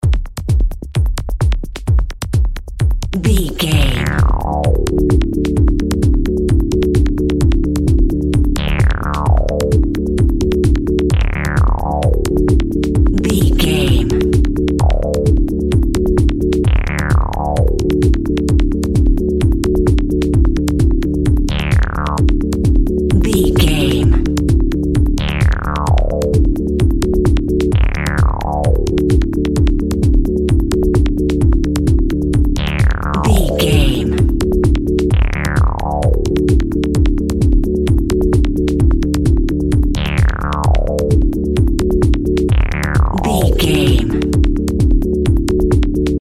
Aeolian/Minor
D
groovy
uplifting
energetic
funky
synthesiser
drum machine
techno
synth lead
synth bass
Synth Pads